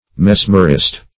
Mesmerist \Mes"mer*ist\, n.
mesmerist.mp3